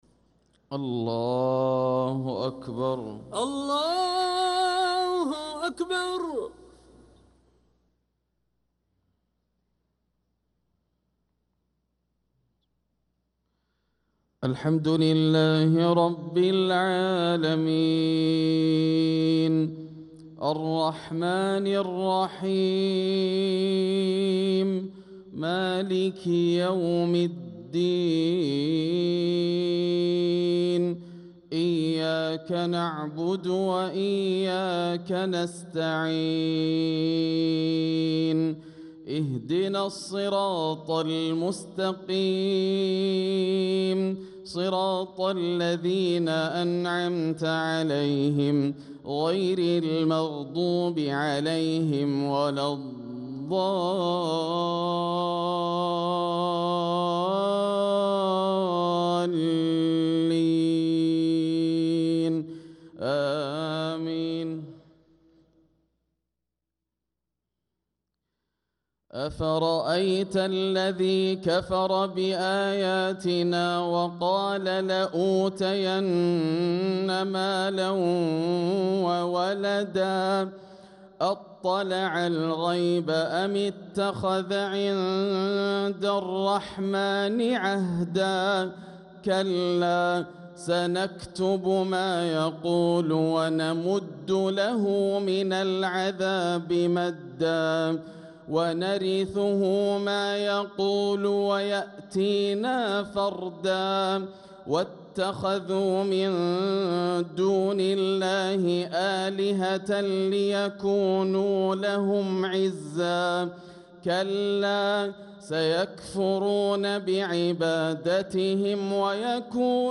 صلاة العشاء للقارئ ياسر الدوسري 19 ربيع الآخر 1446 هـ
تِلَاوَات الْحَرَمَيْن .